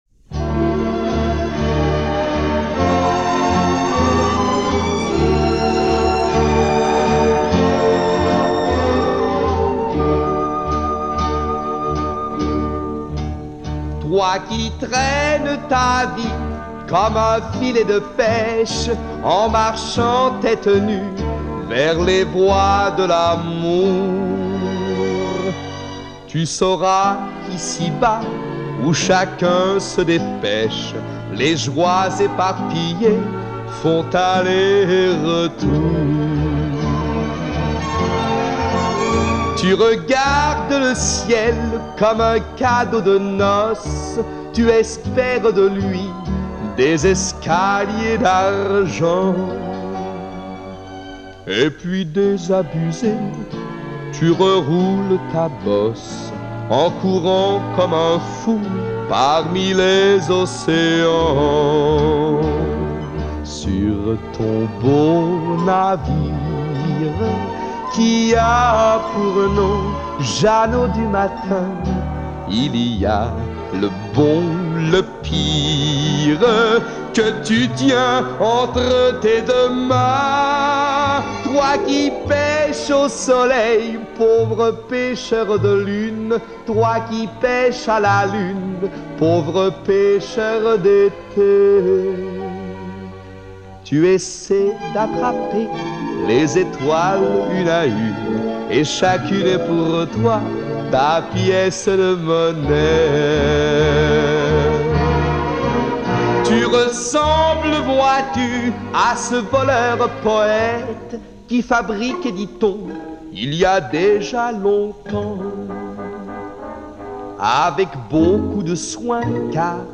Tonight it’s a ballad
The recording on this post is the original 78 release.